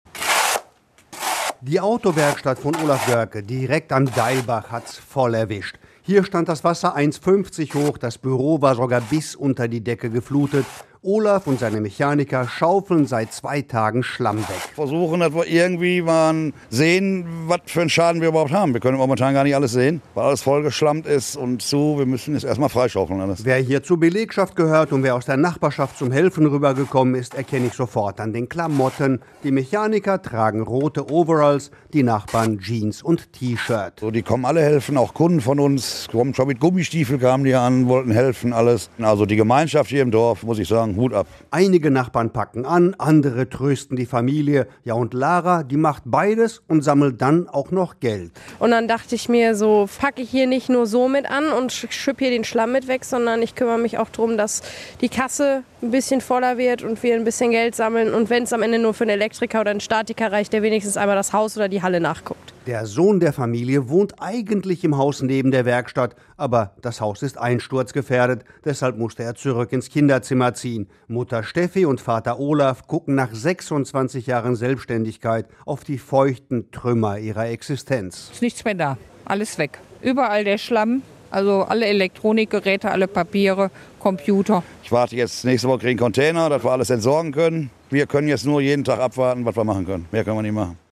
Schlamm putzen in der KFZ-Werkstatt Görke in Kupferdreh